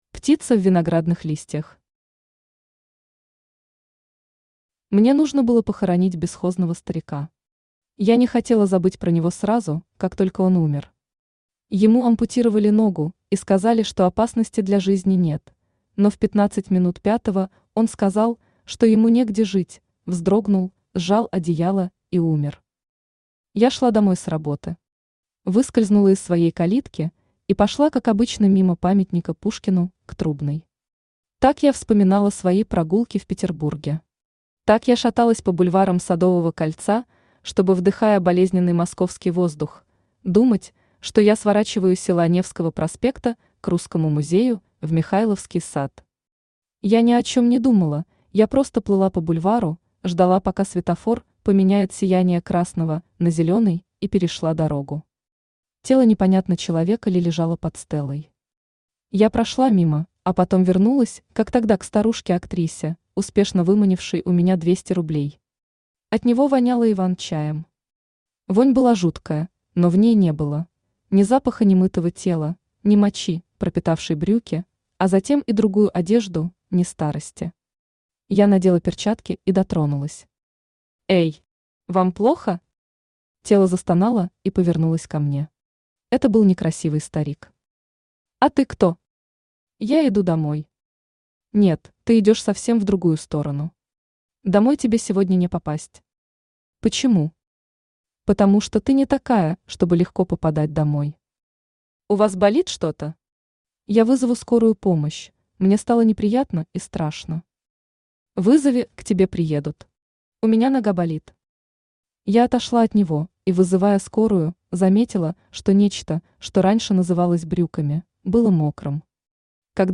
Аудиокнига Птица в виноградных листьях | Библиотека аудиокниг
Aудиокнига Птица в виноградных листьях Автор Оксана Лисковая Читает аудиокнигу Авточтец ЛитРес.